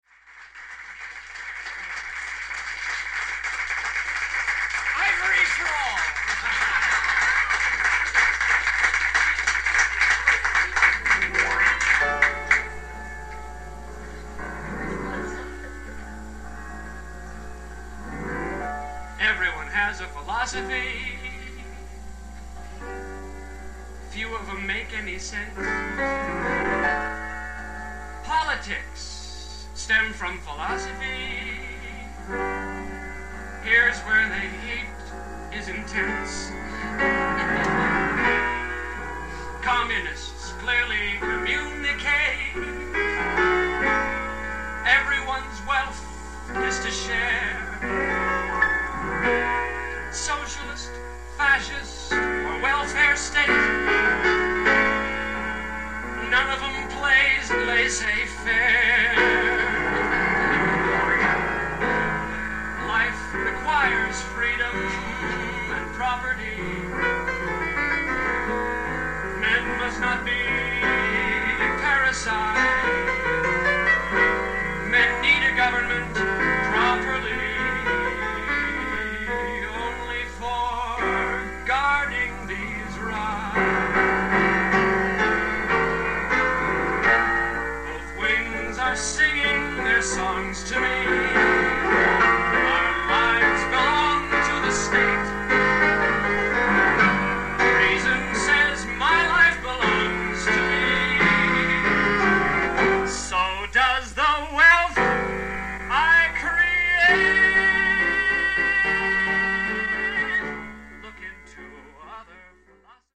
dramatic chords